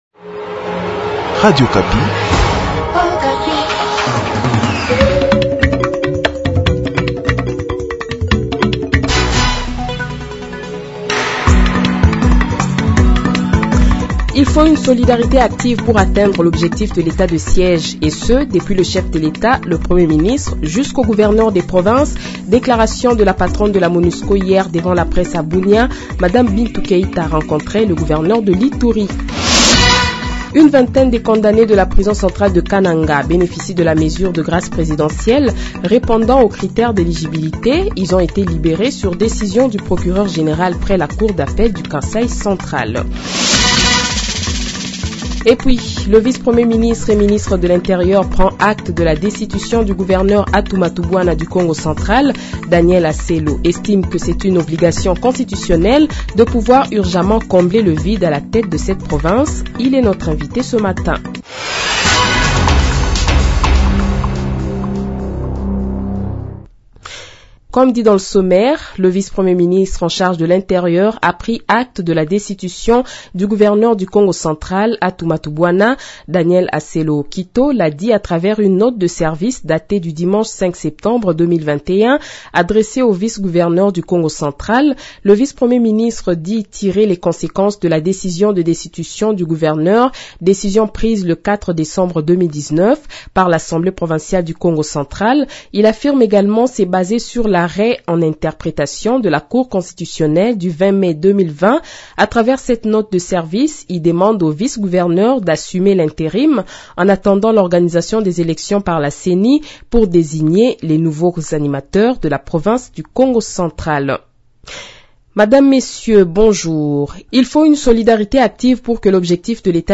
Le journal de 7h, 07 Septembre 2021 :